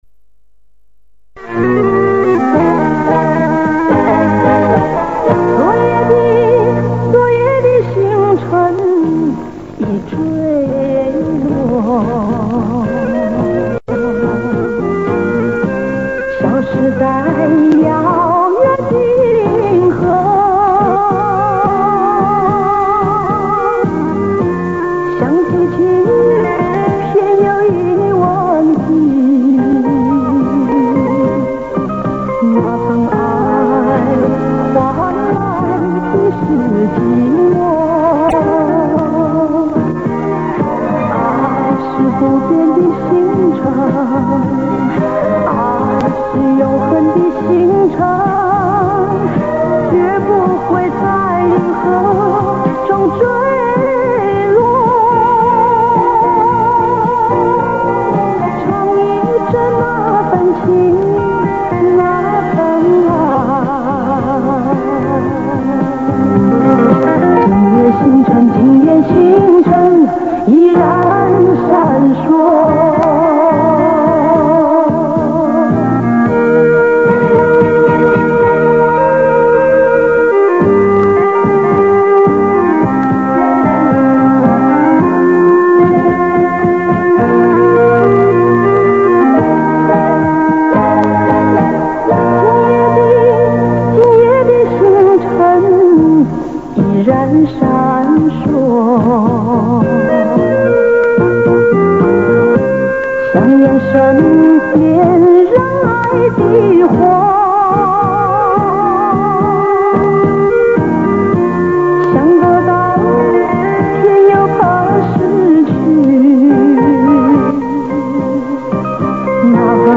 给你从电视上录取的原音
音质差些